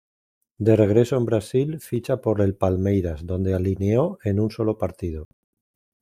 Pronounced as (IPA) /ˈsolo/